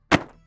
BookPickUp.ogg